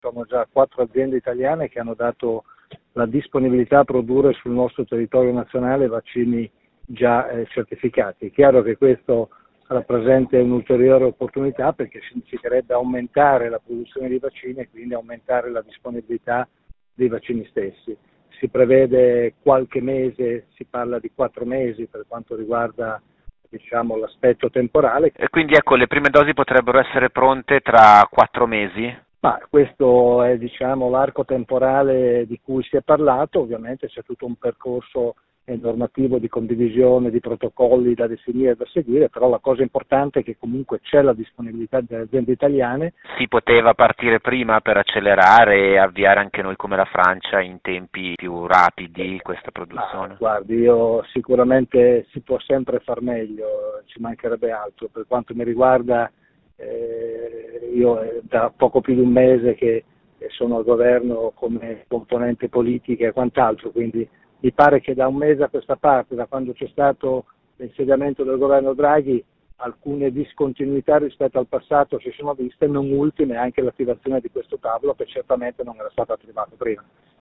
Potrebbe aiutare la produzione nazionale di vaccini, la Francia la avvierà questa settimana. In Italia invece bisognerà aspettare almeno quattro mesi dice il sottosegretario alla salute Andrea Costa